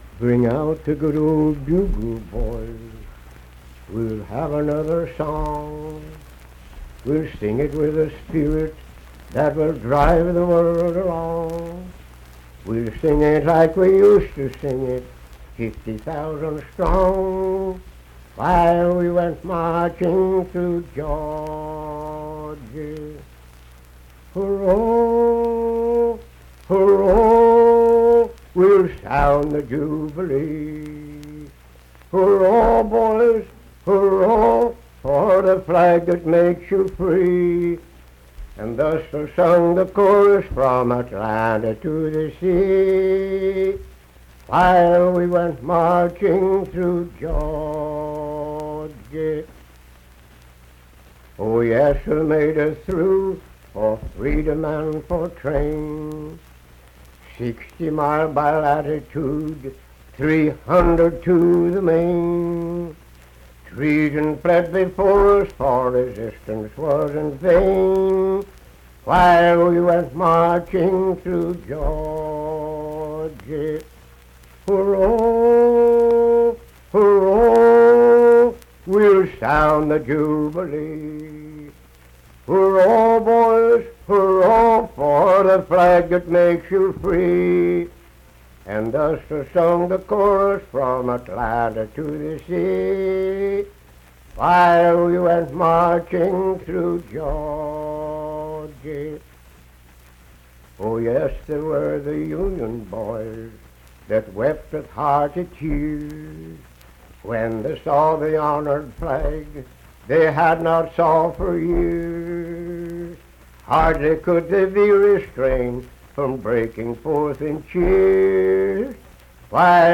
Unaccompanied vocal music performance
Verse-refrain 4d(4w/R) & Rd(4).
Voice (sung)